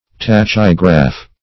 Search Result for " tachygraph" : The Collaborative International Dictionary of English v.0.48: Tachygraph \Tach"y*graph\, n. An example of tachygraphy; esp., an ancient Greek or Roman tachygraphic manuscript.